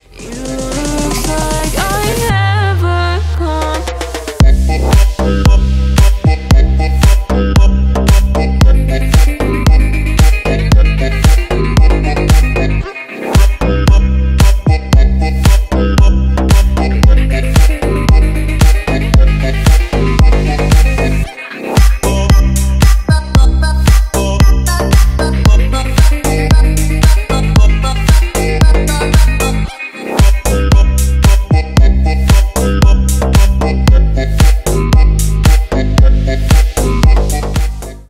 Электроника
клубные # без слов